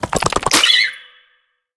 Sfx_creature_penguin_skweak_07.ogg